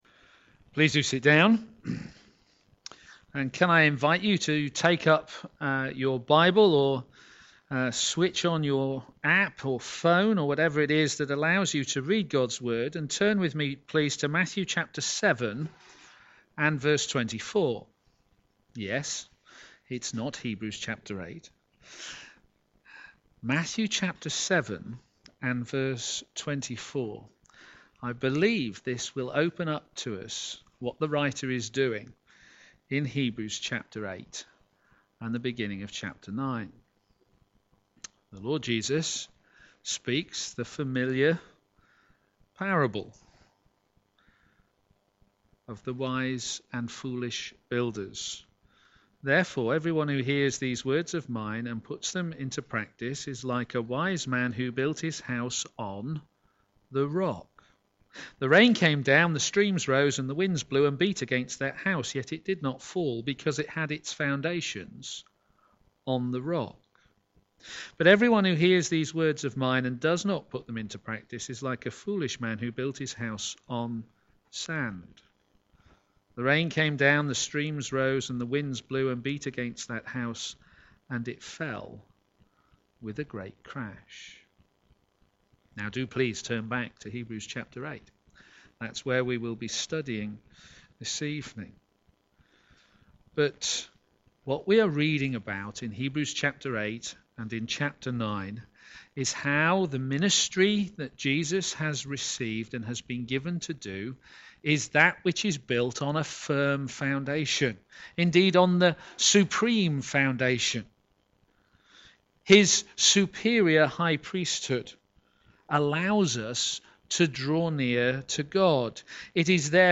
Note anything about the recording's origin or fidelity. p.m. Service